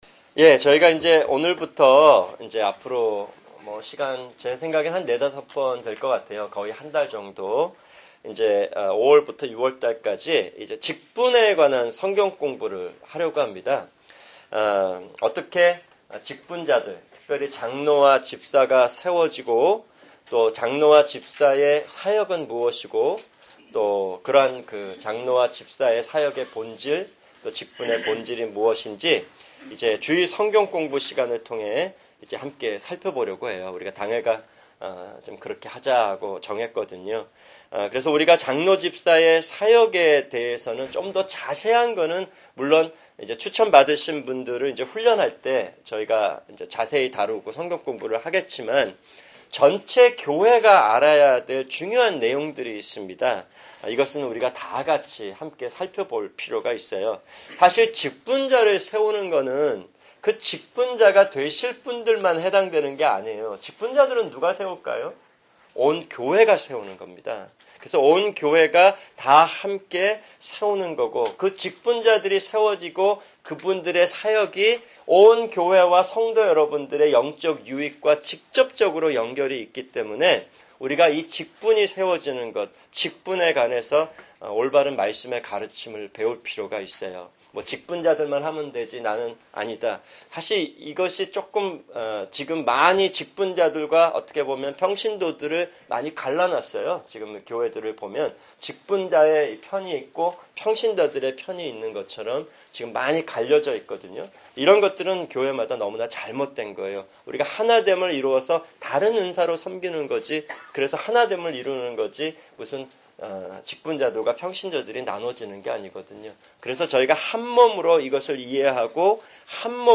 [주일 성경공부] 직분(1)